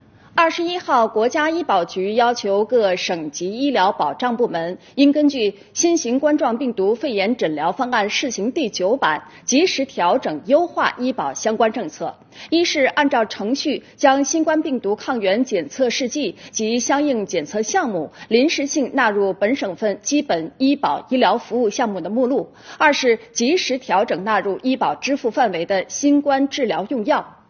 △央视财经《正点财经》栏目视频